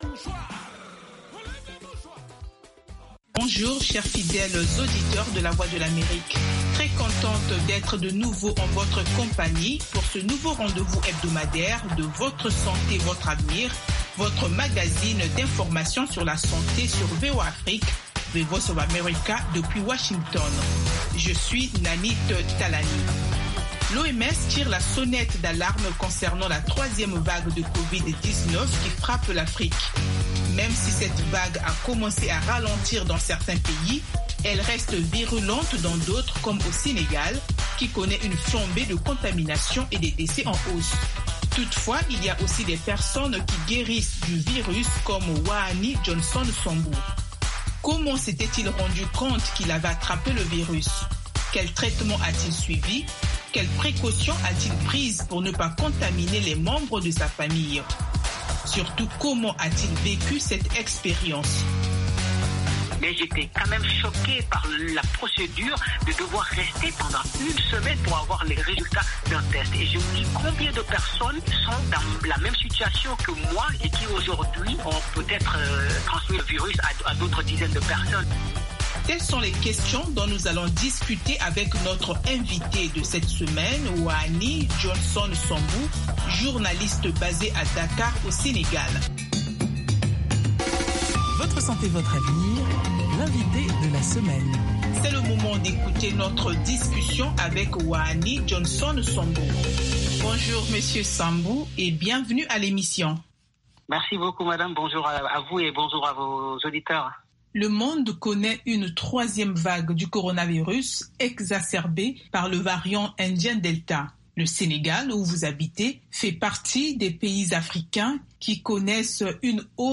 5 Min Newscast